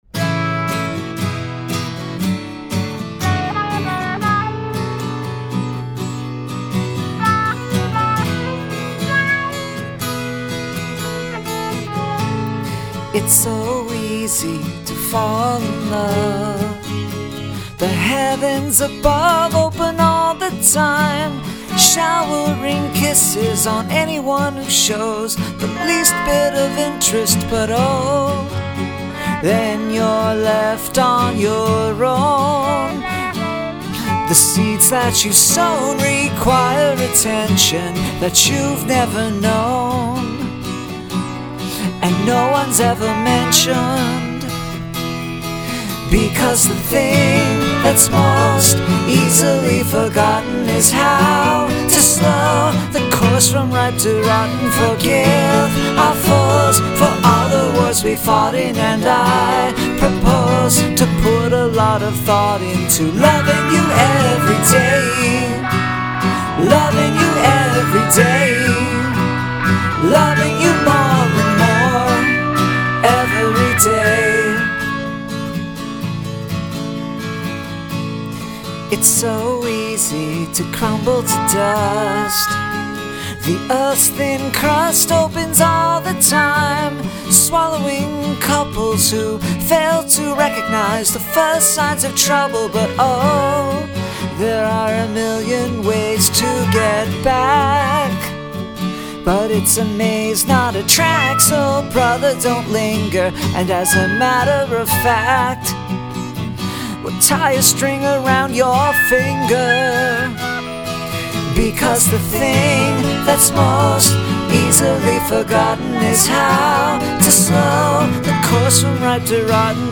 Guitar and Vox only (bass is prohibited)